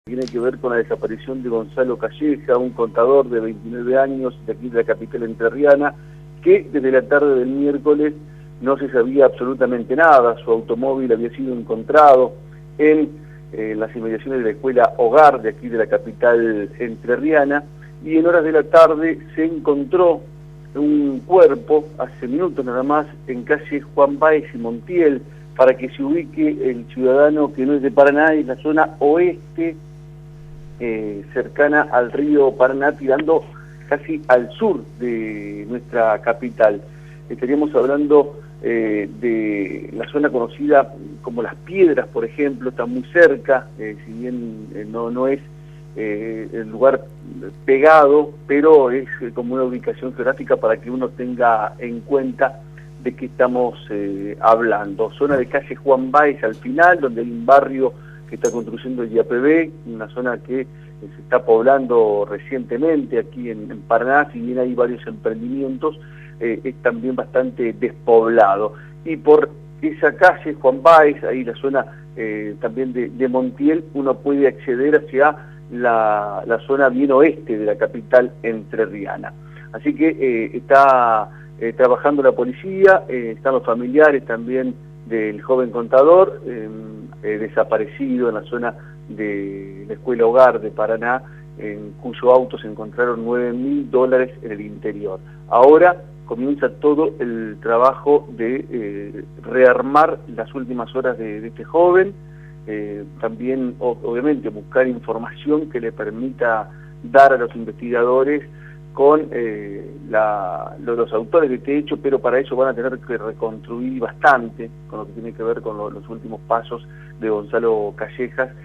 Policiales